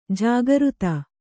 ♪ jāgřta